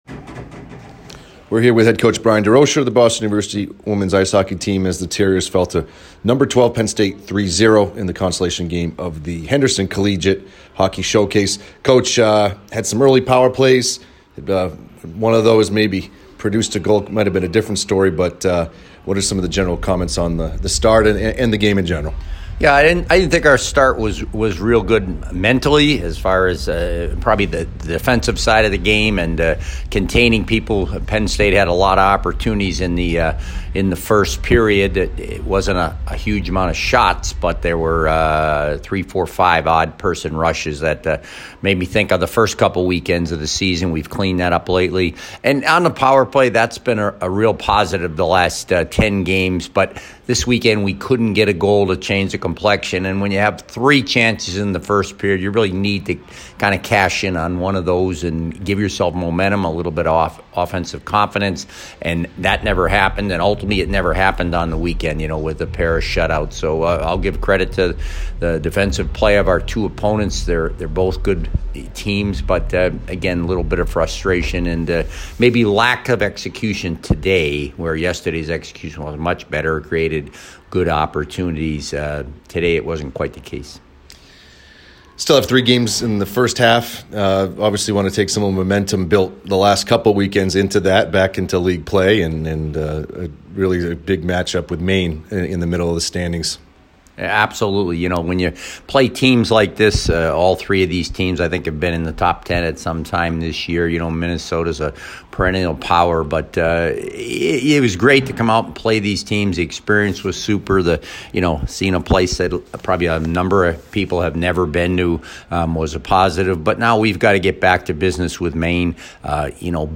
Penn State Postgame Interview